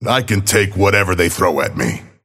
Abrams voice line - I can take whatever they throw at me.